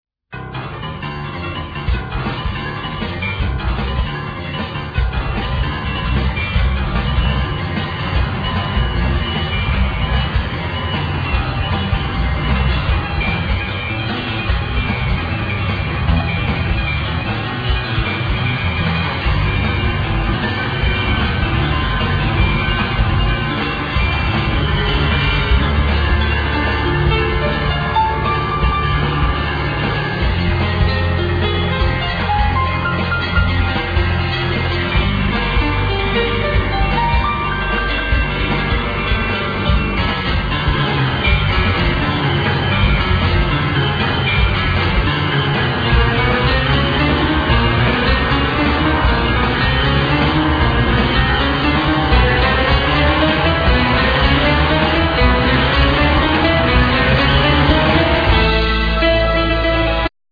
Piano,Synthesizer
Drums
Bass